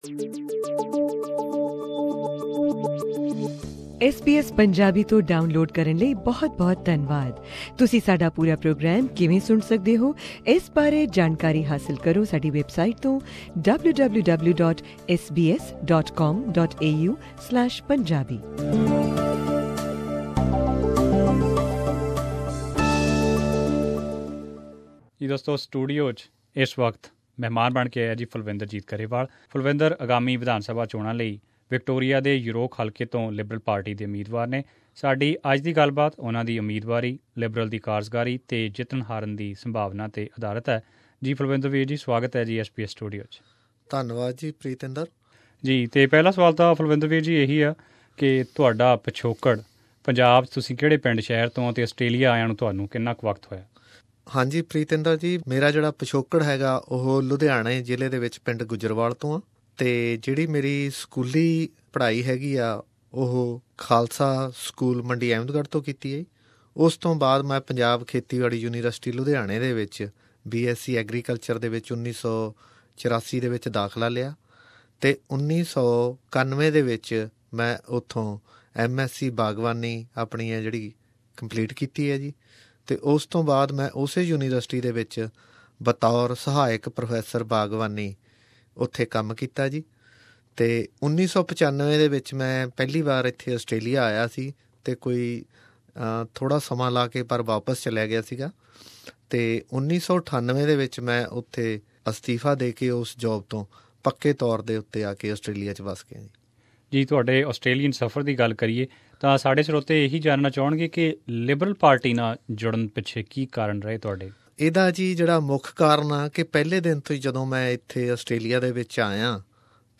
Here we present an interview